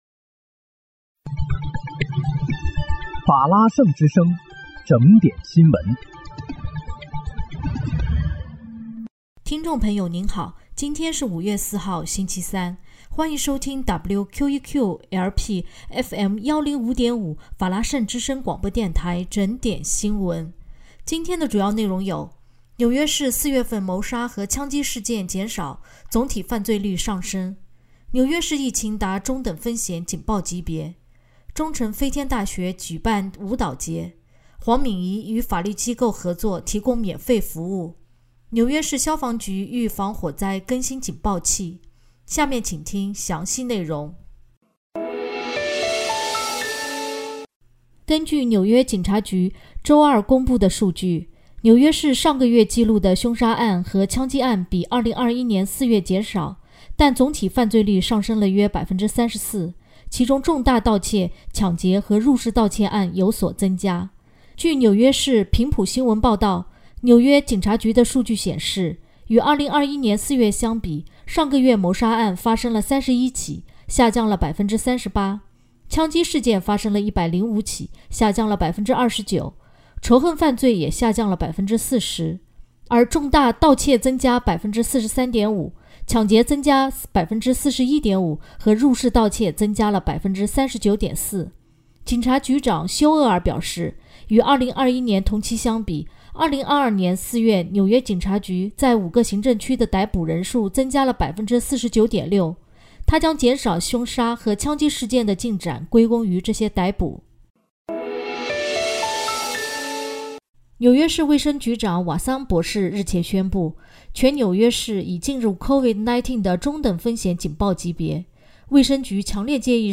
5月4日（星期三）纽约整点新闻
在这里听众朋友您好！今天是5月4号，星期三，欢迎收听WQEQ-LP FM105.5法拉盛之声广播电台整点新闻。